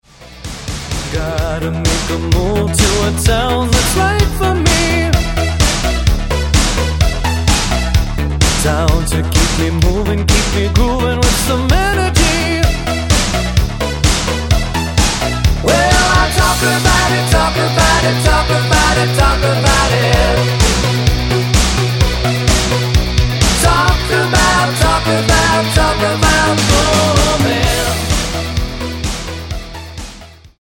--> MP3 Demo abspielen...
Tonart:E Multifile (kein Sofortdownload.